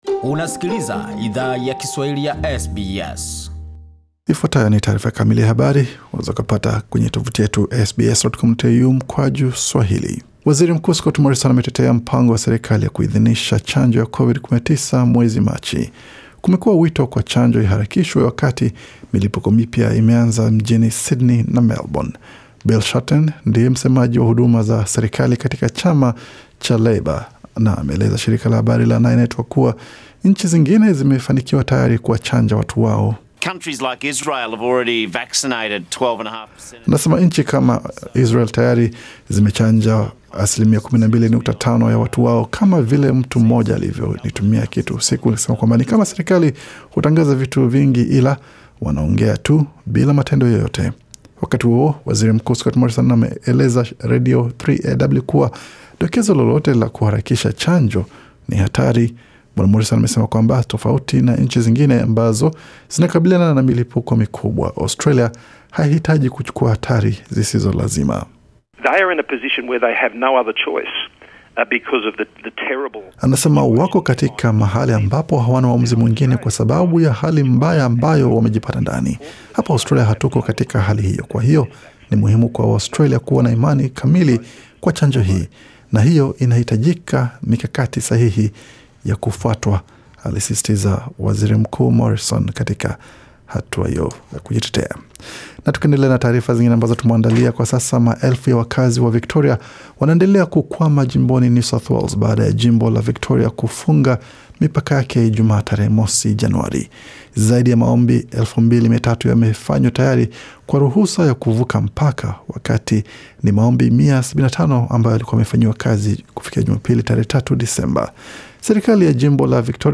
Taarifa ya habari 5 Januari 2021